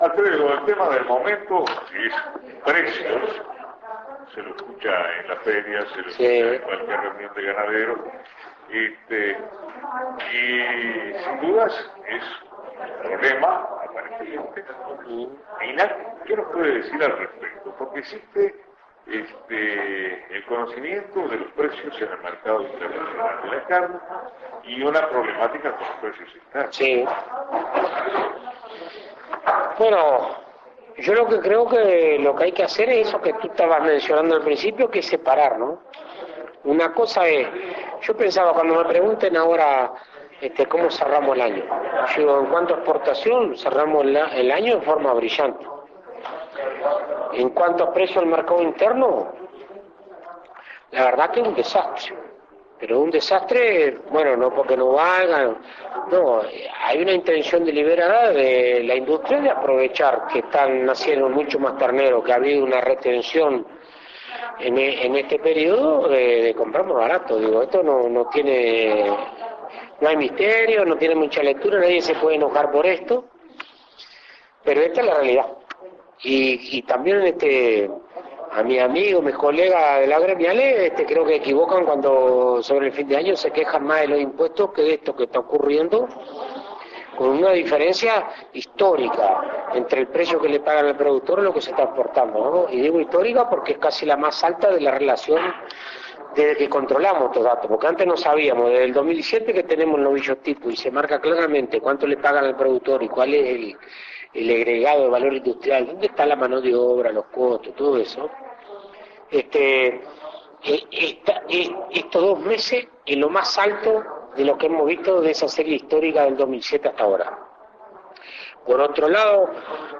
entrevista_a_fratti_-_certificacion.mp3